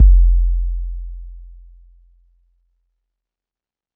SOUTHSIDE_808_original_F.wav